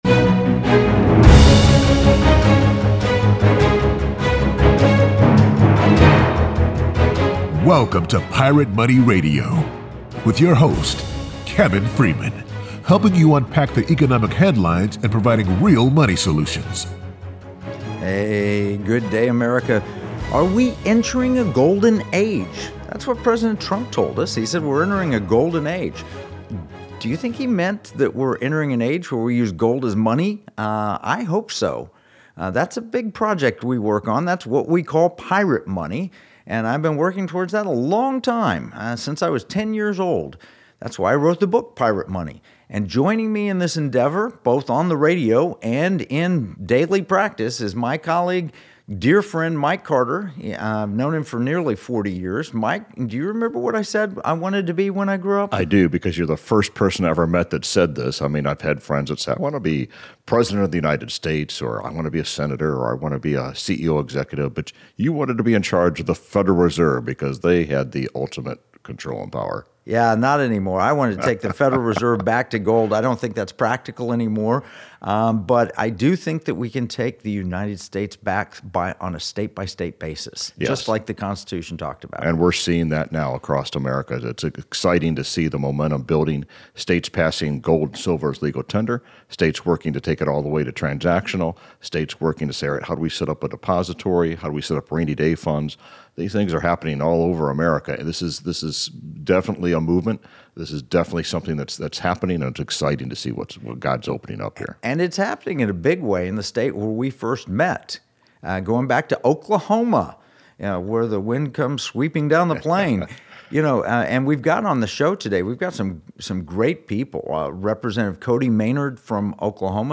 Rumble Video